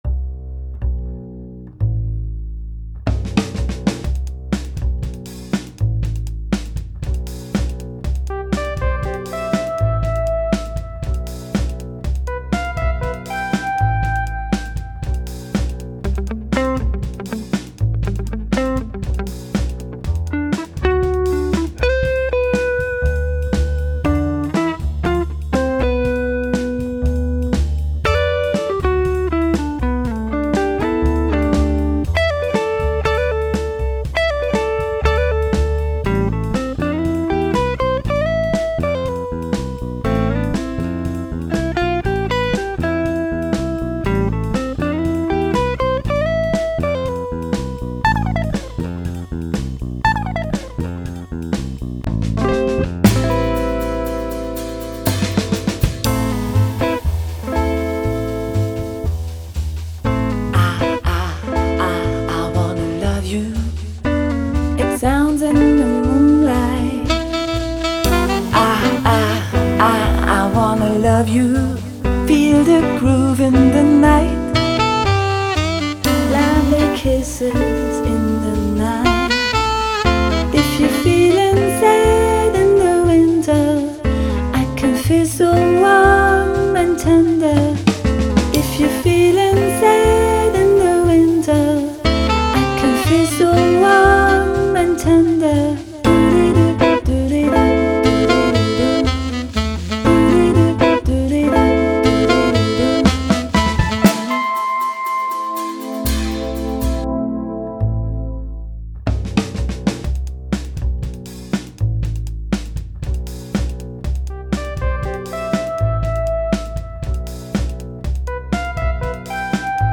με chillout διάθεση